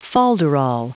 Prononciation du mot folderol en anglais (fichier audio)
Prononciation du mot : folderol